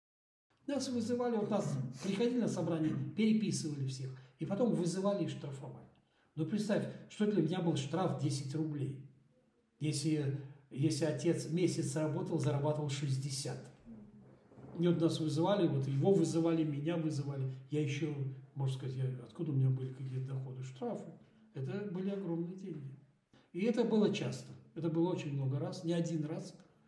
Interviewsequenz Bußgelder